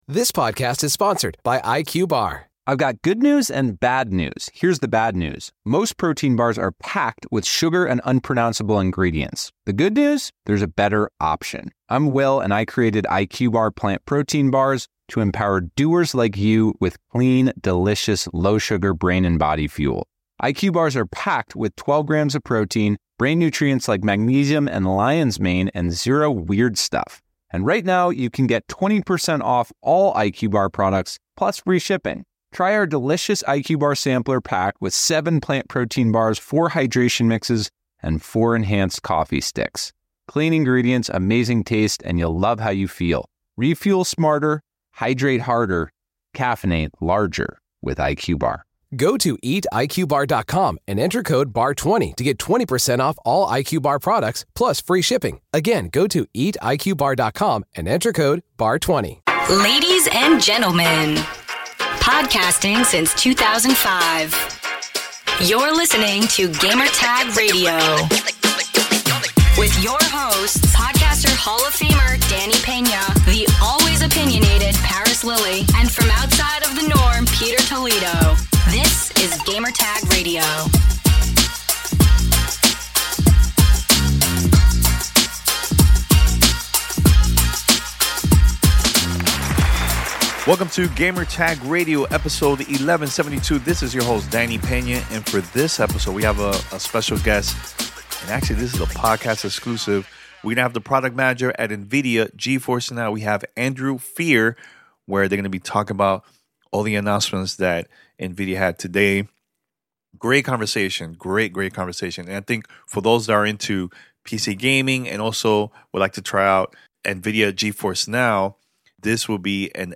NVIDIA GeForce NOW RTX 3080 Interview